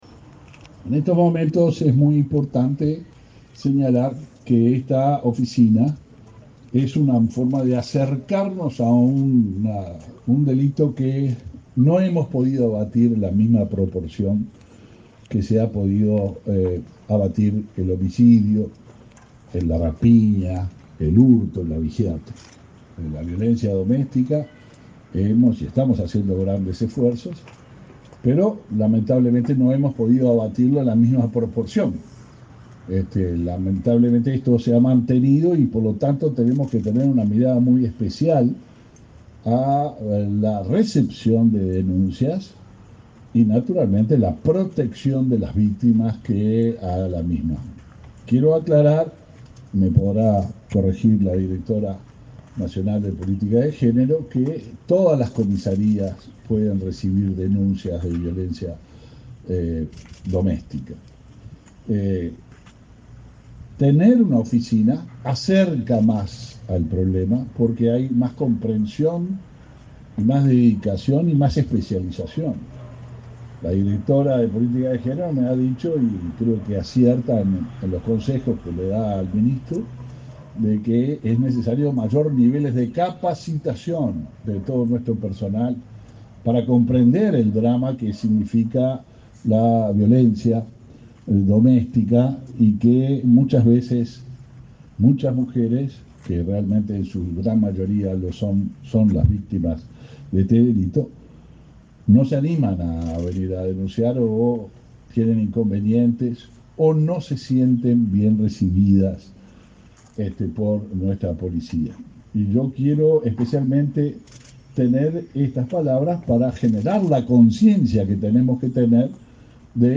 Palabras del ministro del Interior, Luis Alberto Heber
El ministro del Interior, Luis Alberto Heber, participó este viernes 4 en la inauguración de la Oficina de Violencia Doméstica y de Género de Aiguá,